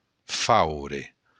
Faure (pronounced [ˈfawɾe]